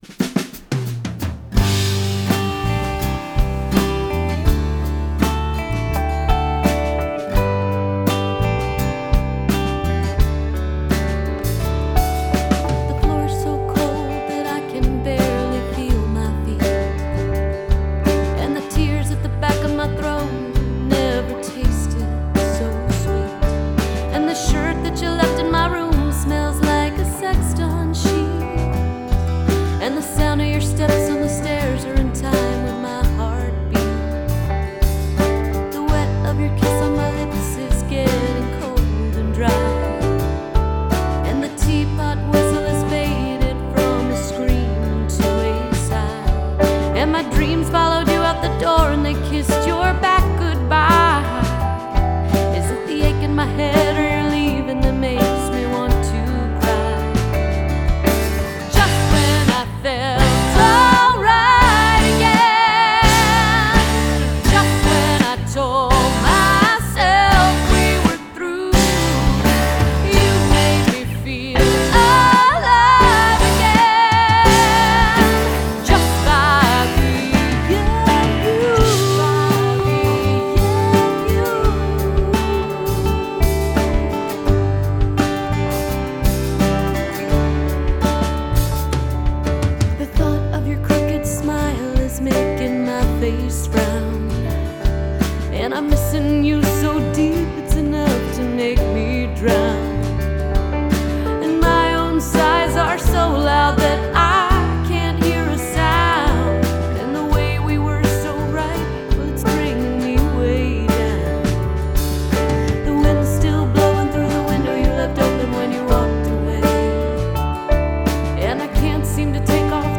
Жанр: Pop, Indie Pop, Country, Singer-Songwriter